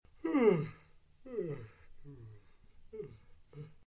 yawn2.ogg